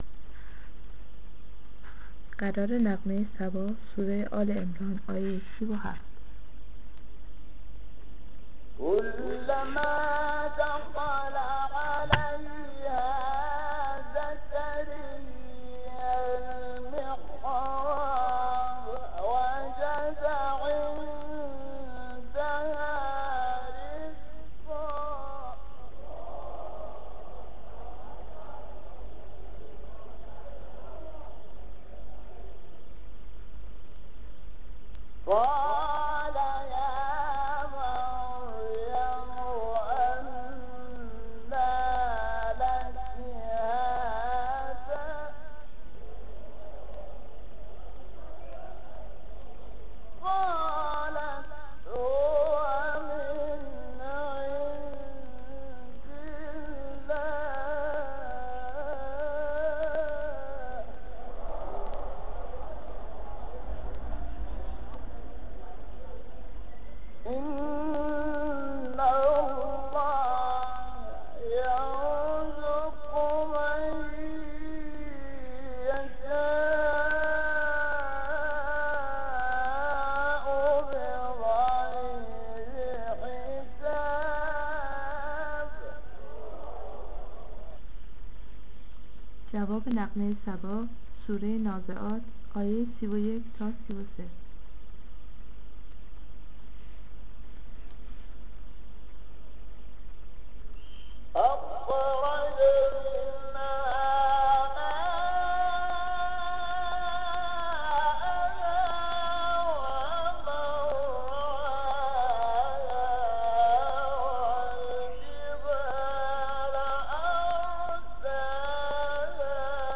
قرار صبا شحات انور سوره آل عمران آیه 37.mp3
قرار-صبا-شحات-انور-سوره-آل-عمران-آیه-37.mp3